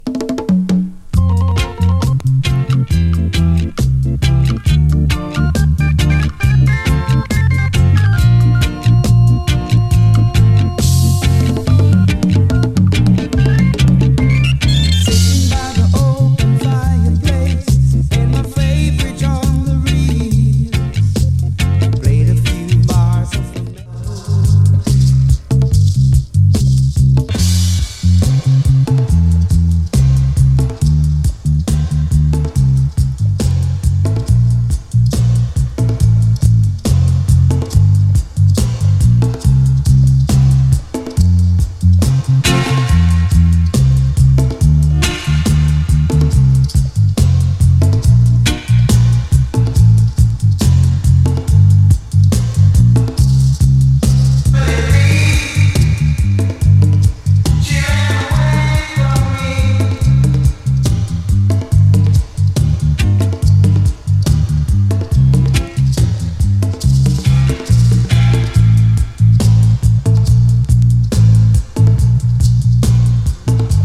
play vocal + dub
extended part with minimoog overdubbings